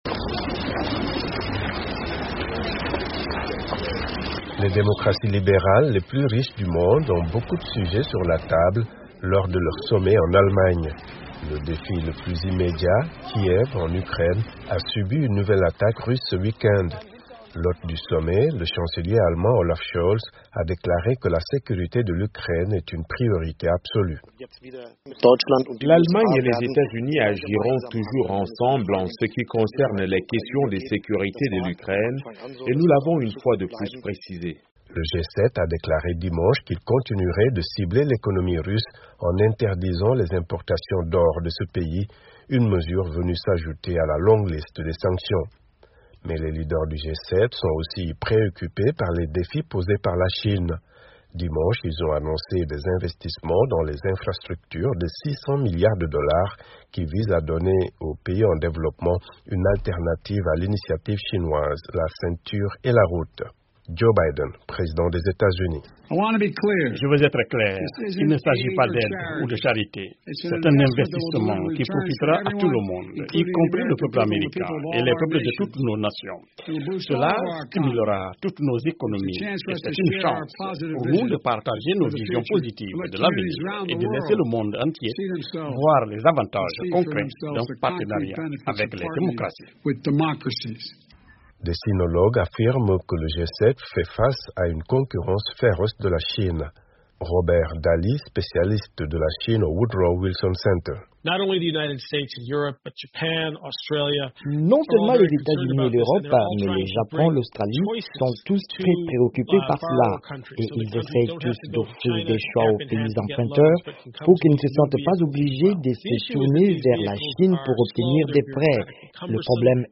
C’est un récit